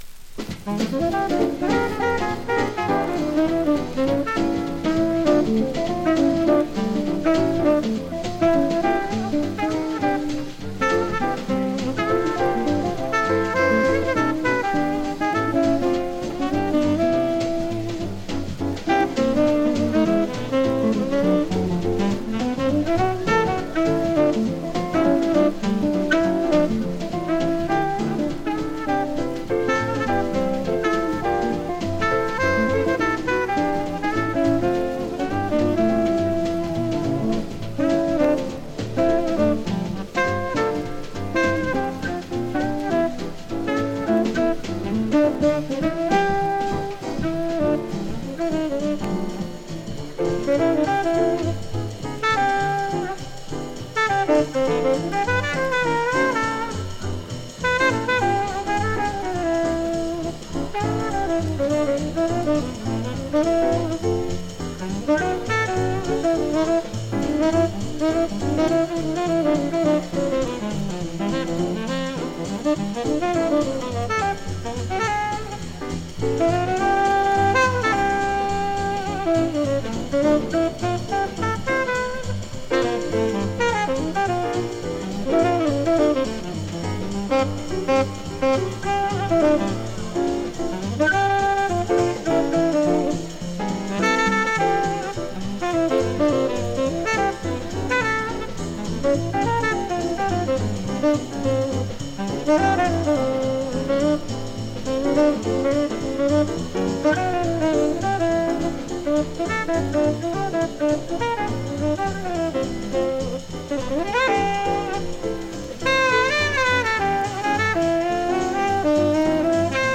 Live好内容盤